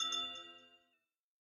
Amethyst_resonate2.ogg